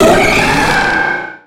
Cri de Méga-Scarhino dans Pokémon X et Y.
Cri_0214_Méga_XY.ogg